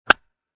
Baseball Bat Hit Sound Effect
The strike of a baseball bat hitting the ball in a game.
Baseball-bat-hit-sound-effect.mp3